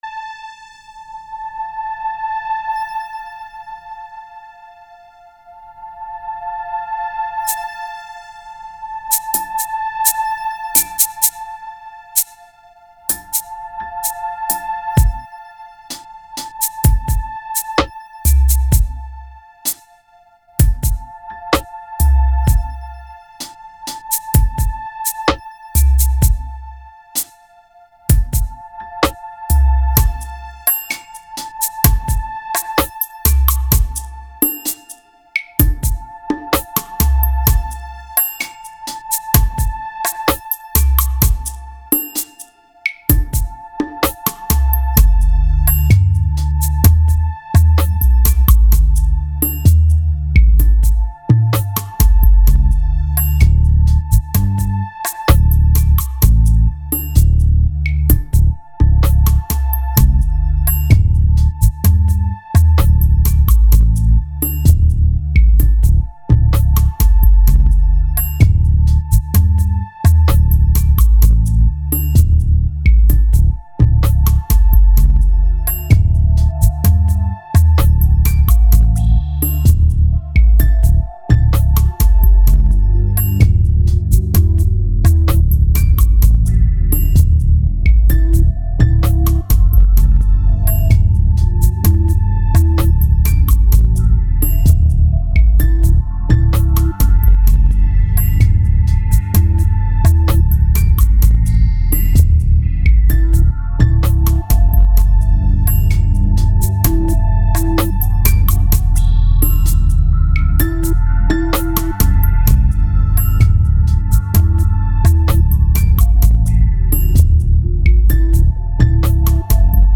2208📈 - -18%🤔 - 64BPM🔊 - 2008-10-18📅 - -294🌟
Slow Motion Drum Percuted Bass Piano
Calm Steady Nuit Night Ambient Sleep